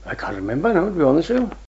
the-phonology-of-rhondda-valleys-english.pdf